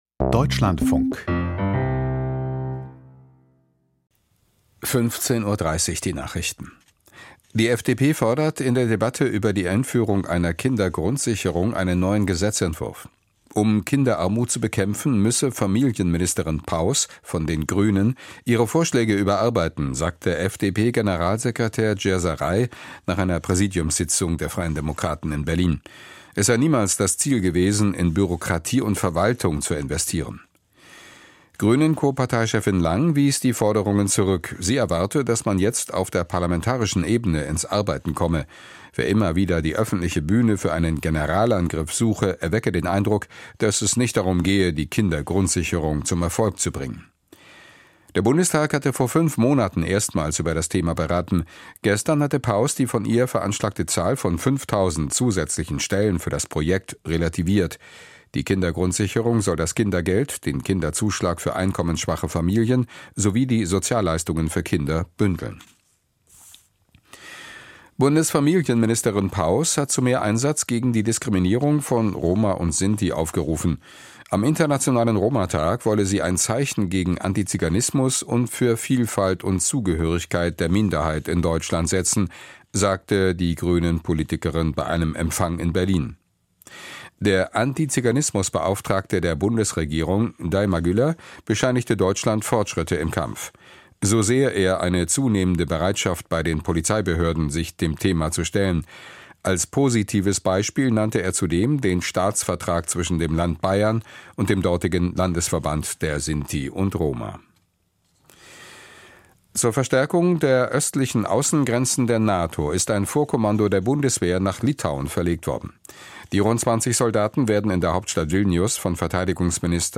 Nach "Manifest": Wo liegen Probleme beim ÖRR? Gespräch